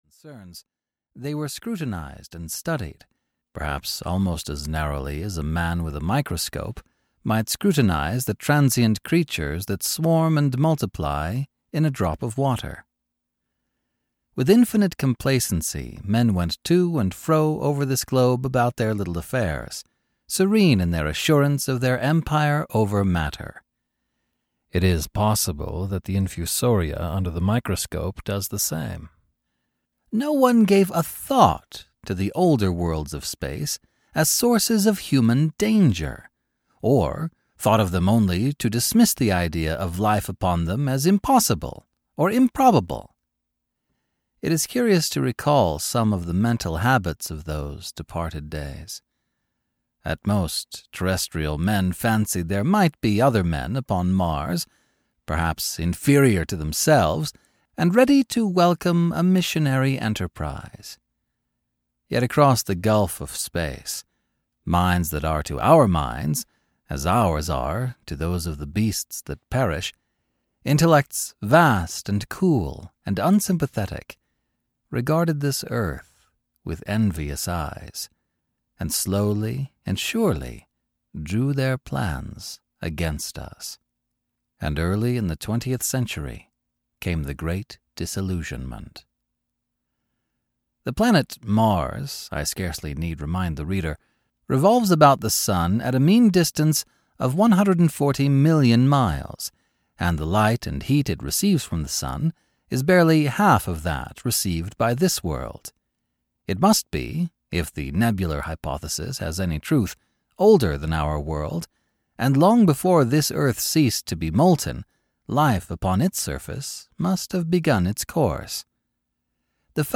Audiobook The War of the Worlds written by H. G. Wells.
Ukázka z knihy